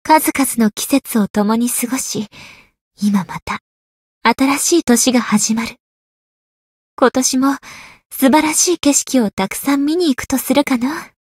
灵魂潮汐-蕖灵-春节（摸头语音）.ogg